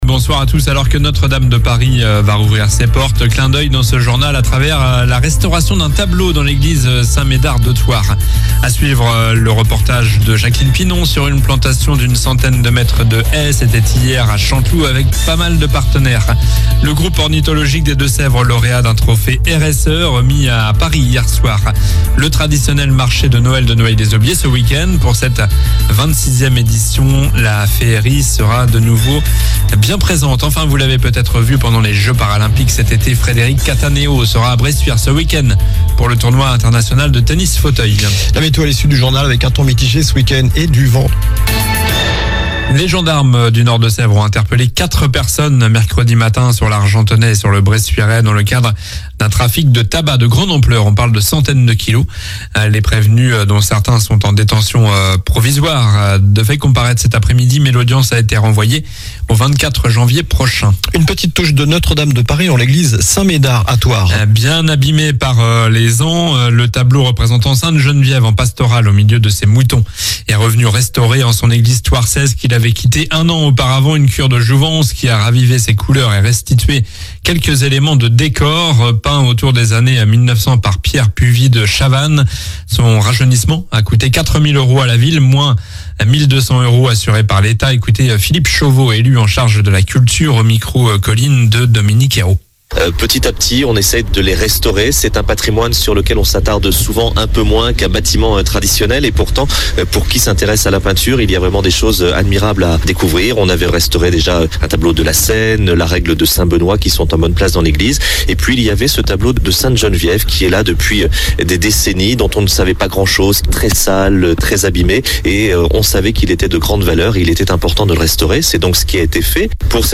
Journal du vendredi 6 décembre (soir)